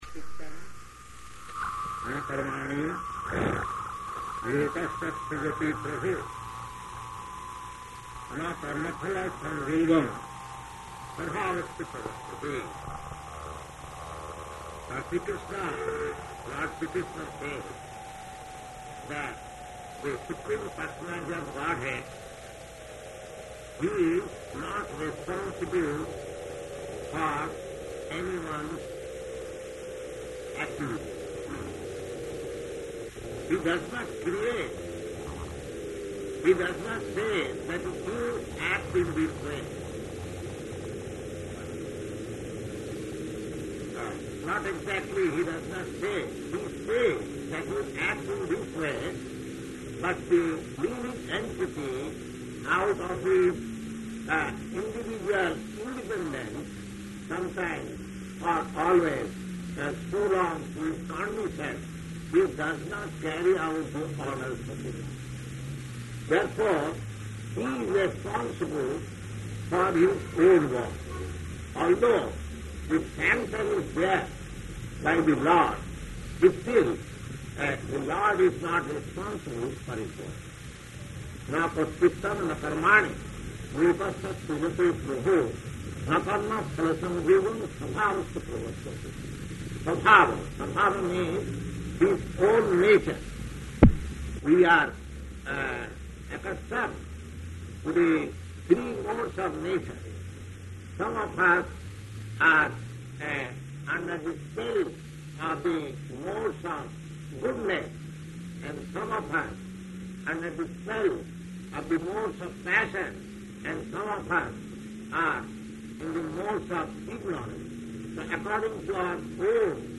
Location: New York
[Bad audio for 2:40 Min]
[02:40 Normal Audio] ...everyone is addressed as jantu.